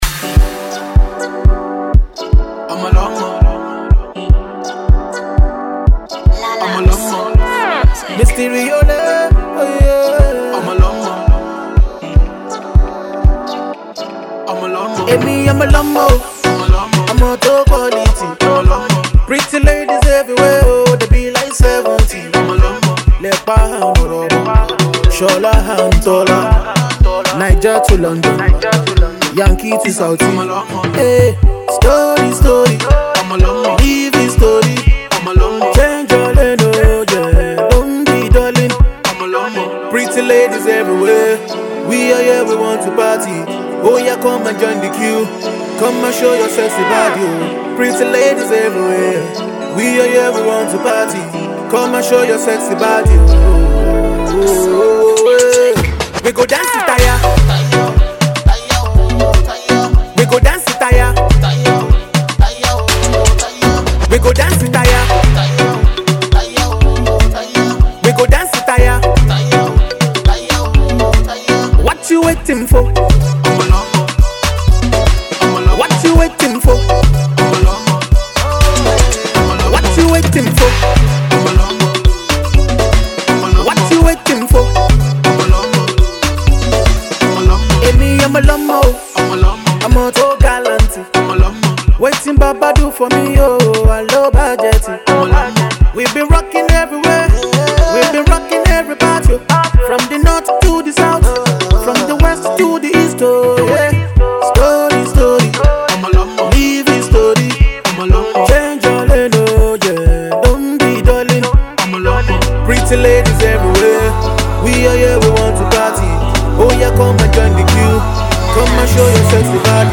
banging tune